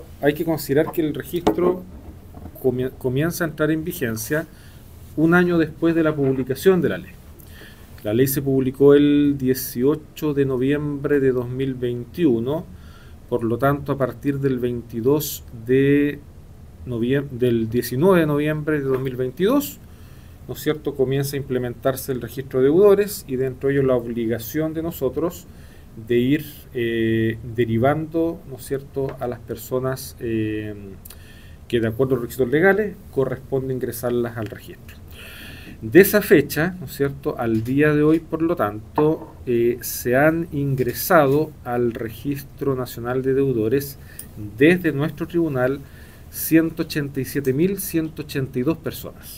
El Juez Titular del Juzgado de Familia de Osorno, Mauricio Rause Staub, indicó que desde la implementación del Registro Nacional de Deudores en noviembre del año 2022, se ha ingresado 187.182 personas.